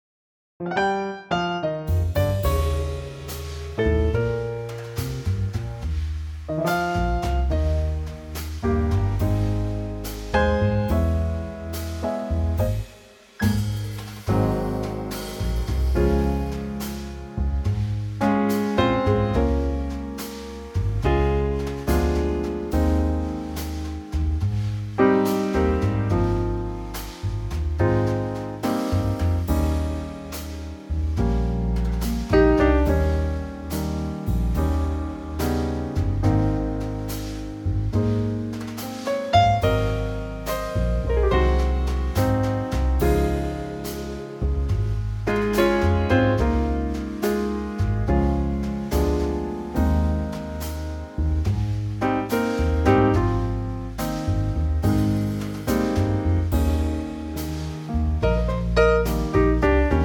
Unique Backing Tracks
key - Ab - vocal range - Ab to Ab
Lovely Trio arrangement